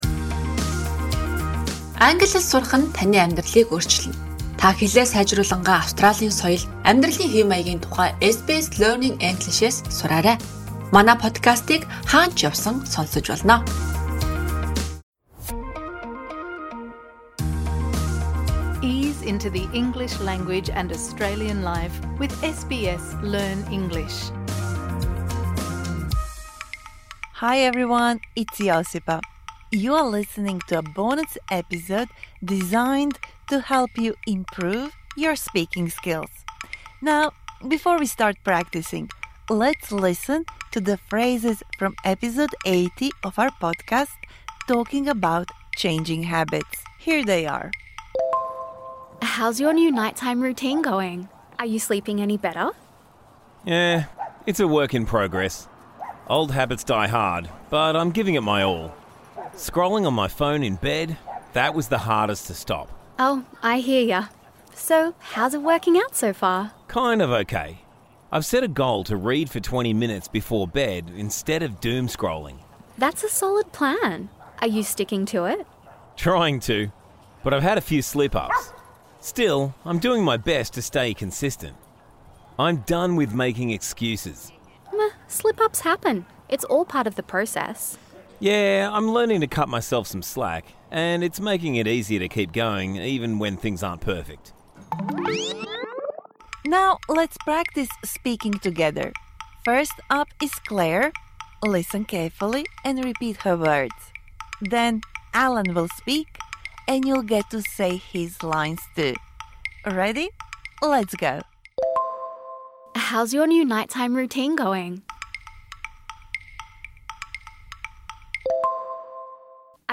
This bonus episode provides interactive speaking practice for the words and phrases you learnt in Episode #81 Talking about changing habits Don't be shy - just try!